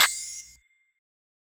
clap 10 (mira type).wav